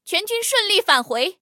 黑豹战斗返回语音.OGG